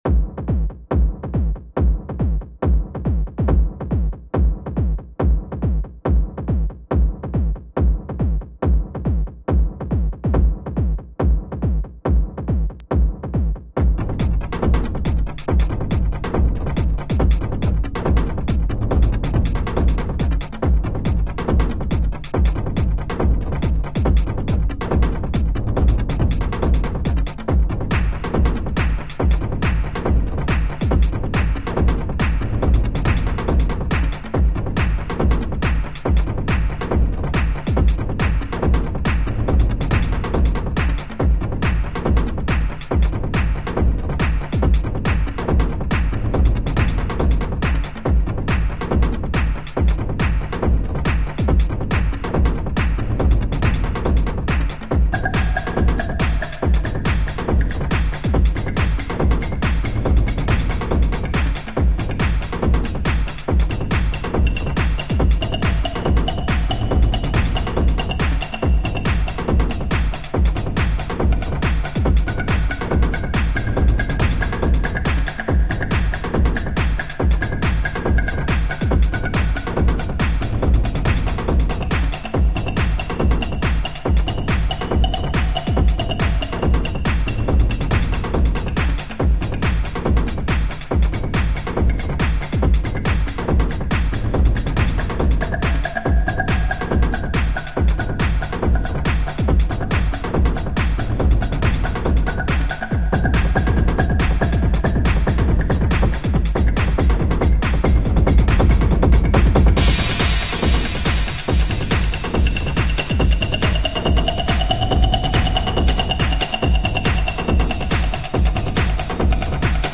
techno tribal percussive house